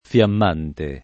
fLamm#nte]